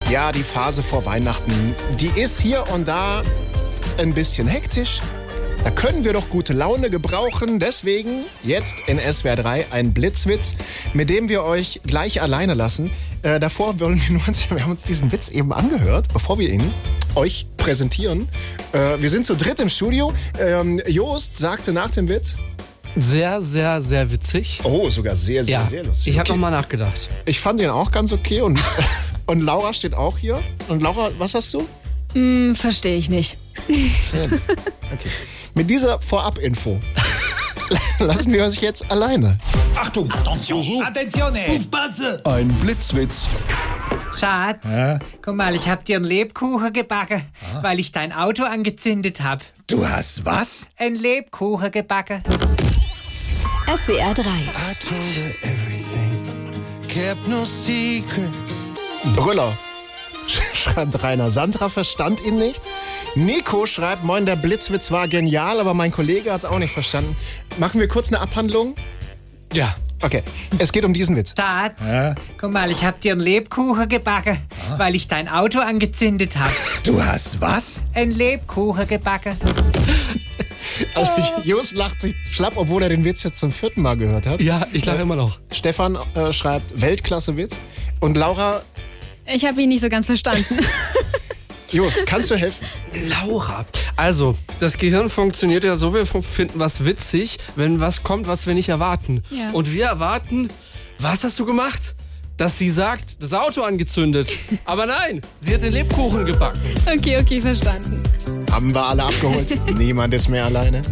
Comedy
Sendmitschnitt etwas rausklammern und hier reinstellen...